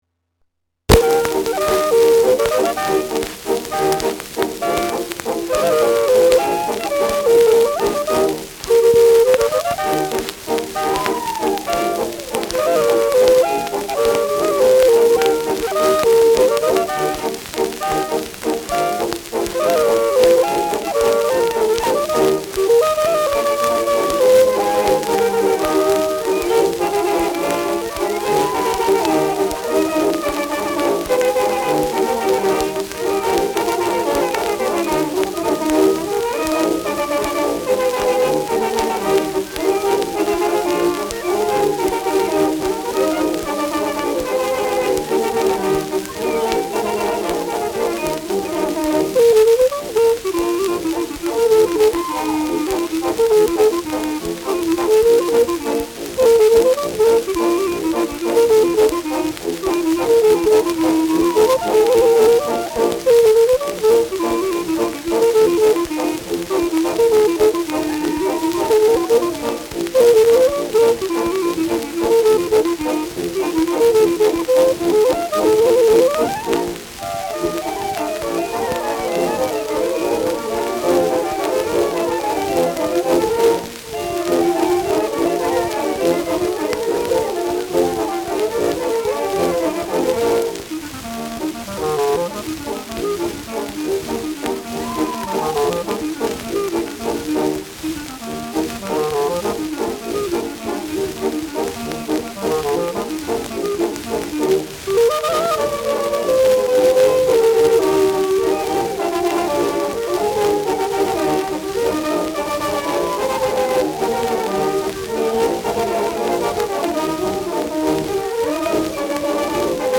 Schellackplatte
wegen Bruchs fehlender Anfang : präsentes Rauschen
Stadtkapelle Fürth (Interpretation)